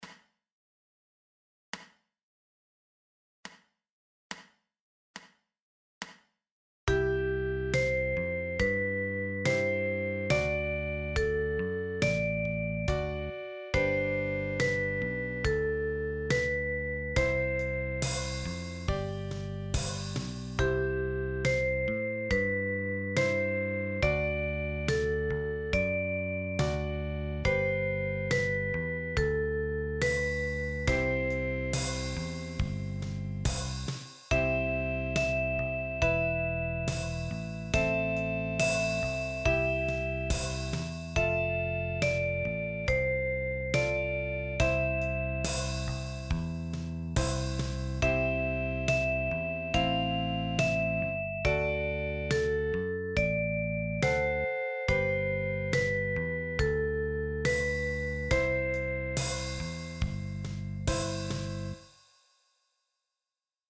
Sound samples – melody & band and band alone: